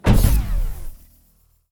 More SFX
Stomp1.wav